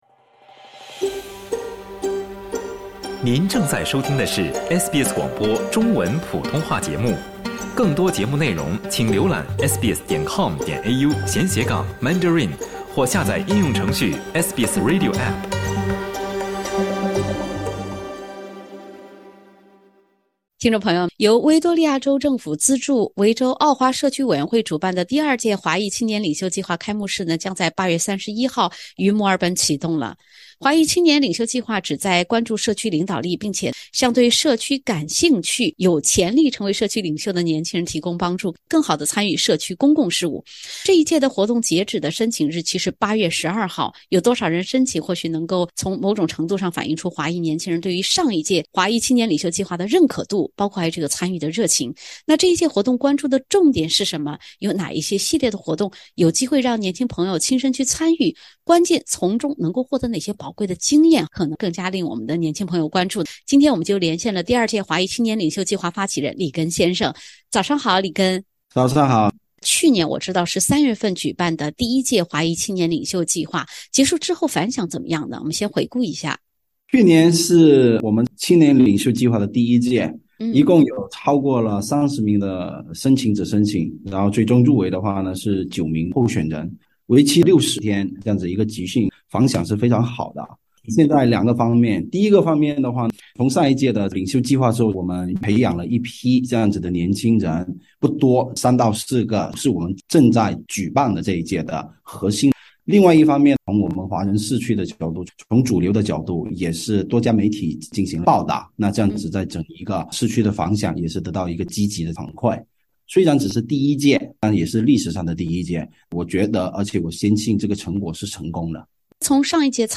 “华裔青年领袖计划”旨在关注社区领导力，欢迎对社区公共事务感兴趣并有潜力成为社区领袖的年轻人参与其中。（点击封面图片，收听完整对话）